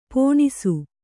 ♪ pōṇisu